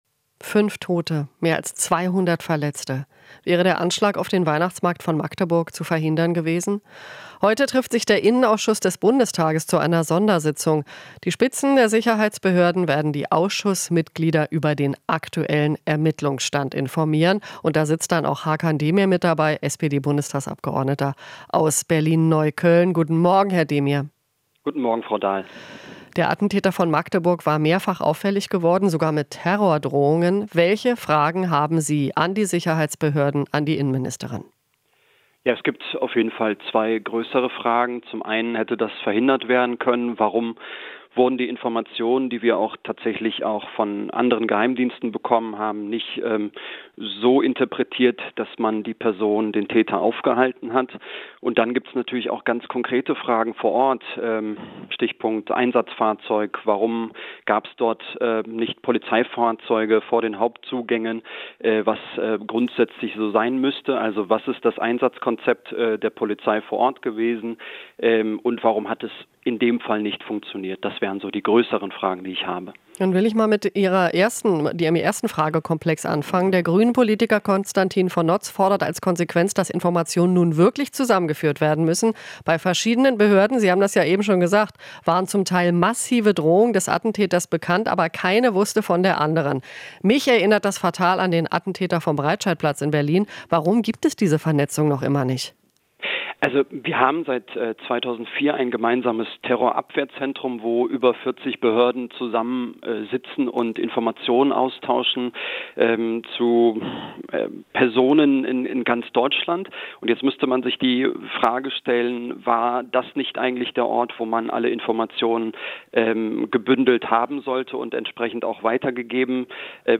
Interview - Demir (SPD): Hätte Anschlag von Magdeburg verhindert werden können?